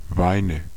Ääntäminen
Ääntäminen Tuntematon aksentti: IPA: /ˈvaɪ̯nə/ Haettu sana löytyi näillä lähdekielillä: saksa Käännöksiä ei löytynyt valitulle kohdekielelle. Weine on sanan Wein monikko.